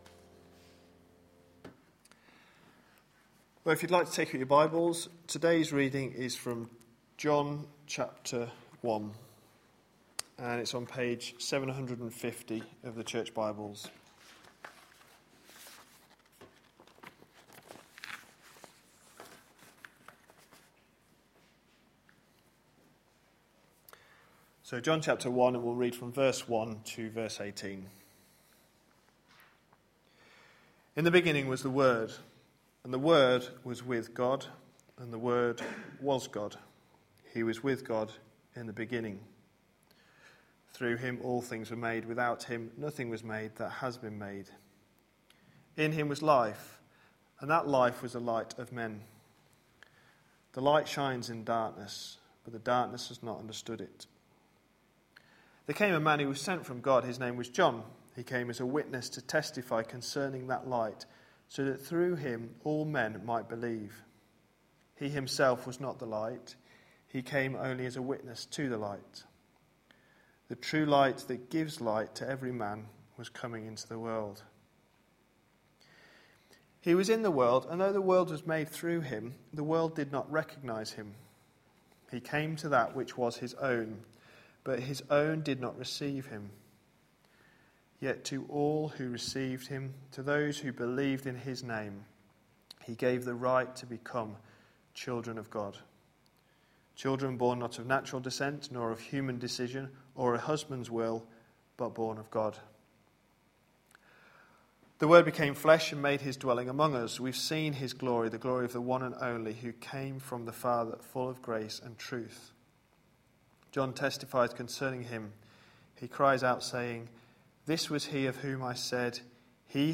A sermon preached on 23rd December, 2012.